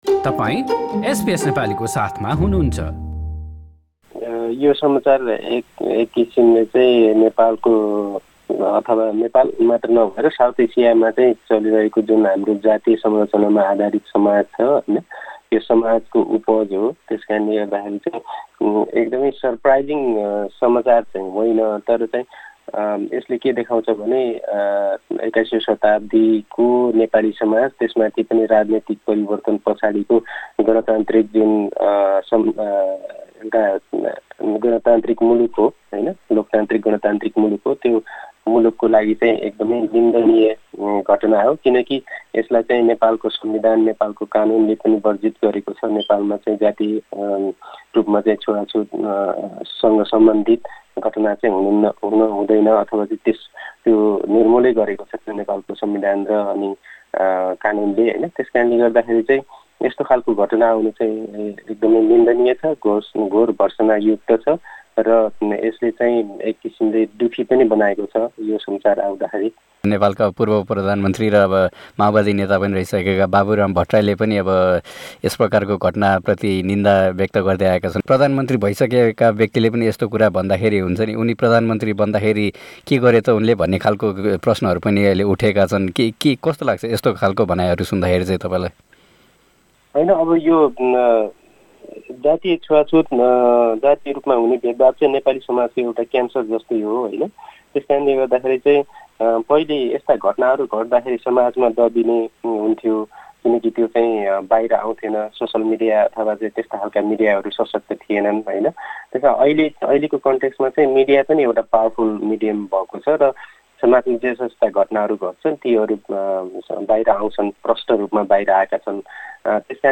कुराकानी सुन्न माथि रहेको मिडिया प्लेयरमा किल्क गर्नुहोस् संयुक्त राष्ट्रसंघको मानव अधिकार उच्चायुक्तले नेपालमा अन्तरजातीय विवाहका कारणले थुप्रै युवाले ज्यान गुमाउनु परेका घटनाको निन्दा गर्दै ती घटनामाथि स्वतन्त्र अनसन्धान हुनुपर्ने बताएकी छिन्।